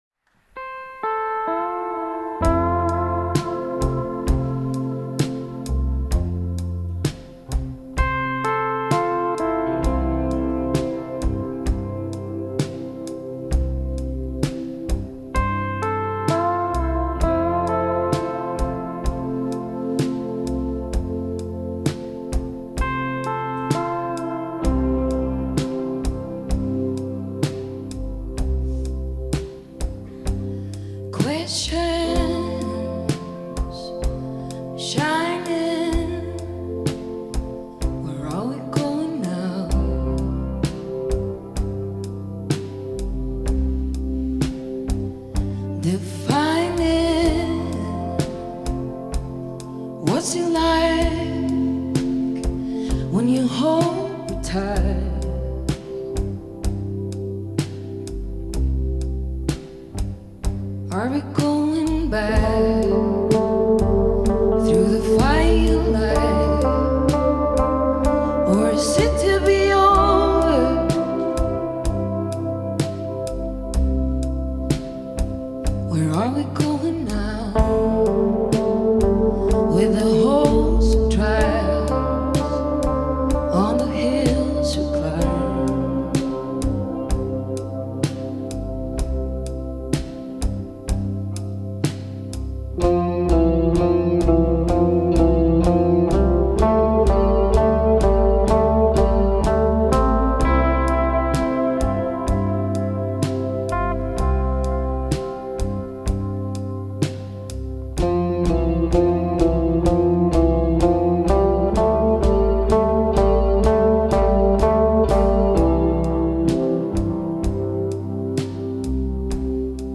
Swedish Folk/Indie Singer-Songwriter
Sumptuous sounds from Sweden.